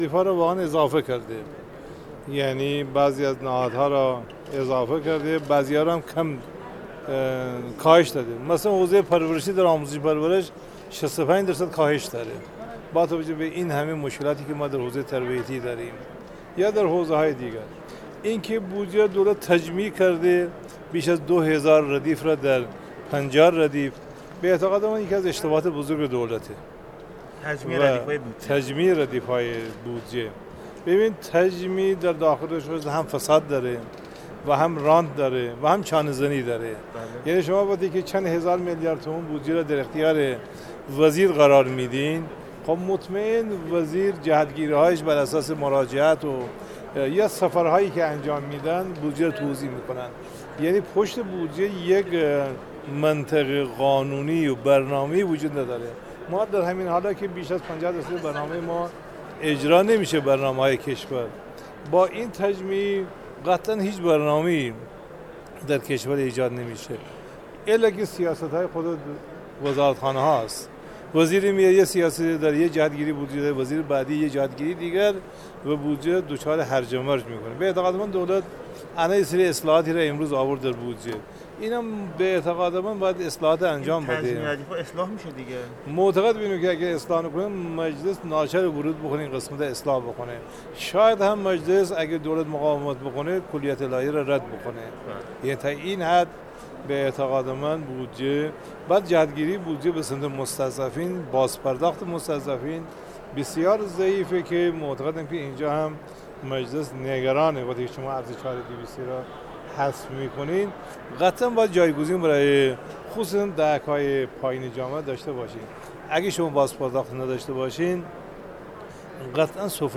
کوچکی‌نژاد در گفت‌وگو با ایکنا بیان کرد: